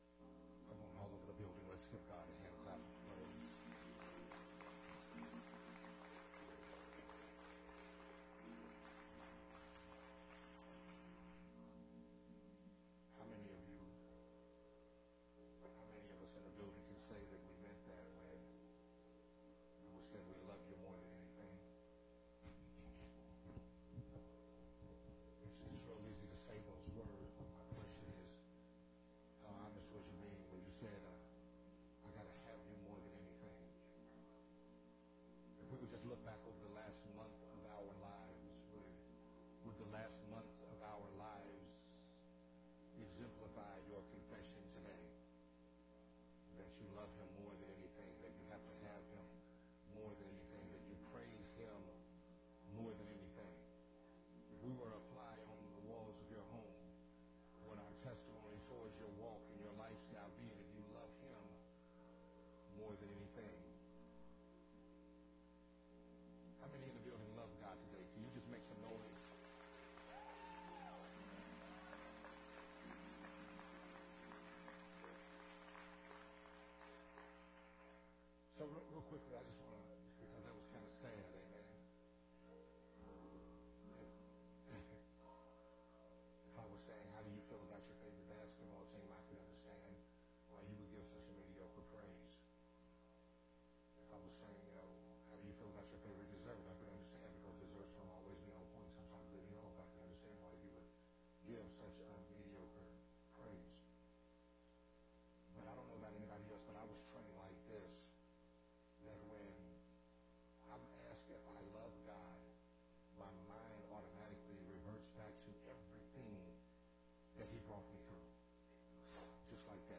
Sunday Morning Worship Service